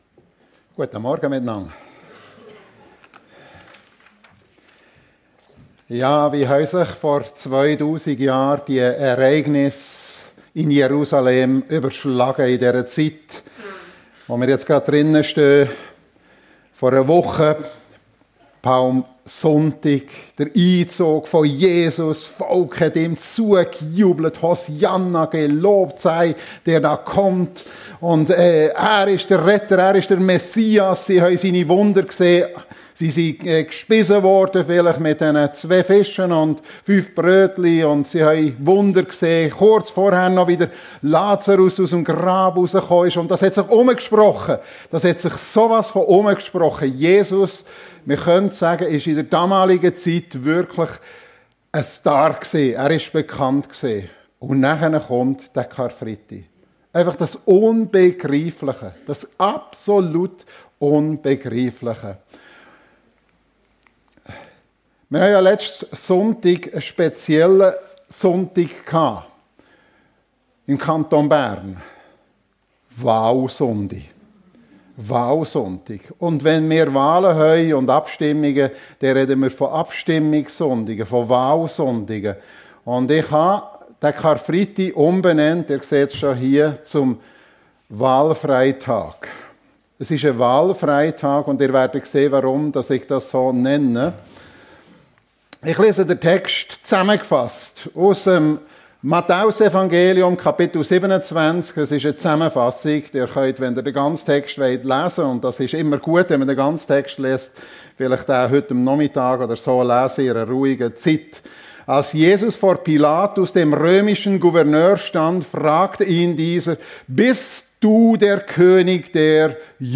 (Wahl-) Freitag ~ FEG Sumiswald - Predigten Podcast